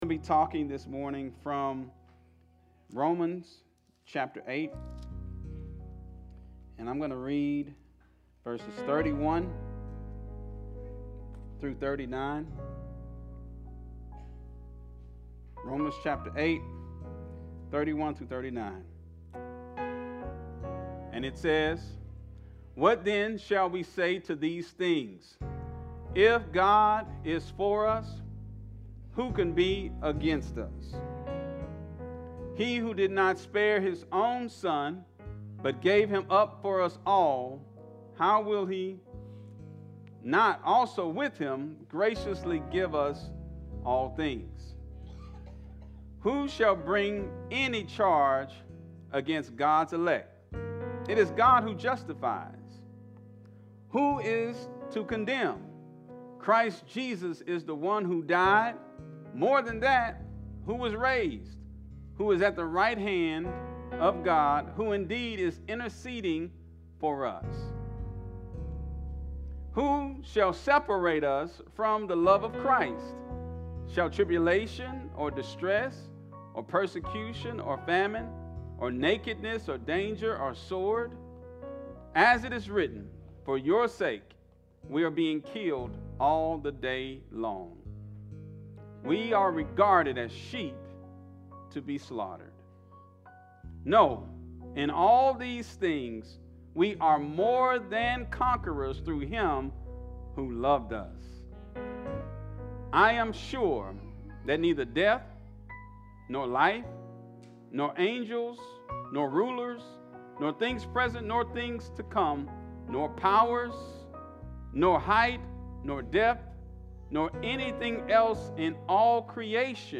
Sermons | Growing Faith Fellowship